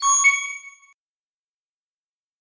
Звук отправленного сообщения